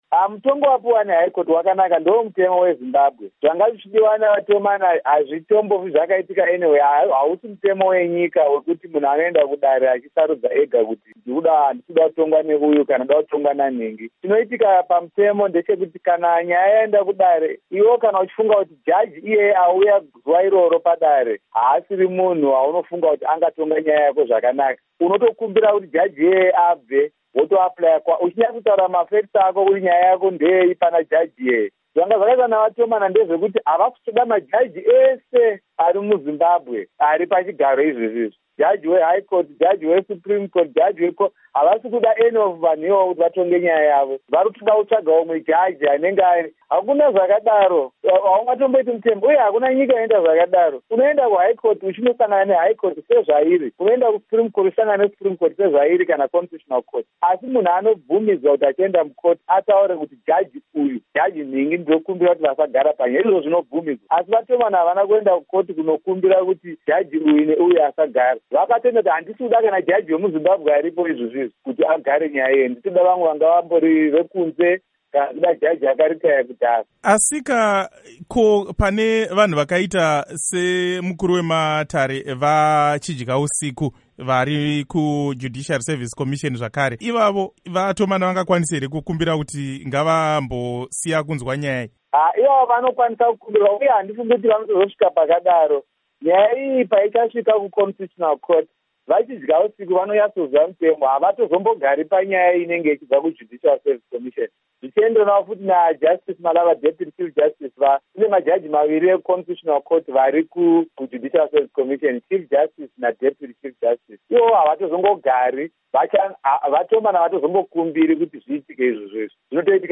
Hurukuro naVaJohannes Tomana